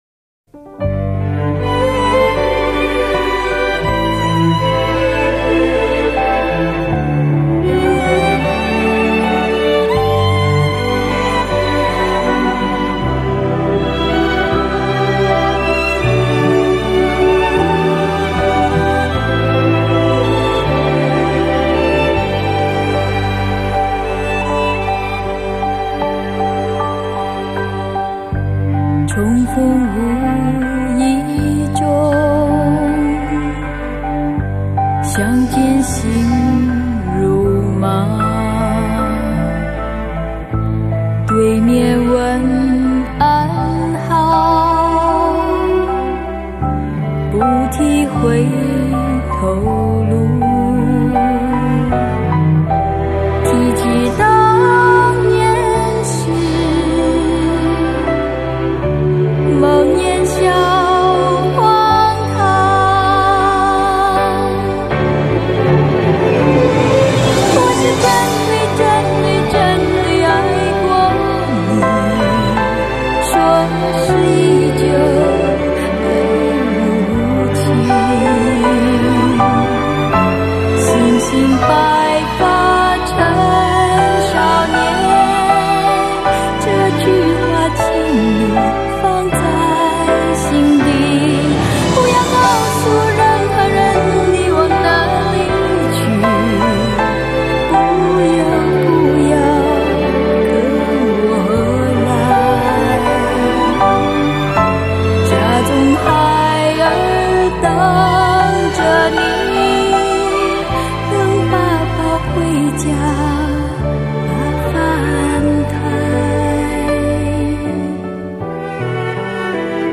弦乐编曲也较出色.